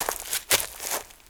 MISC Leaves, Foot Scrape 01.wav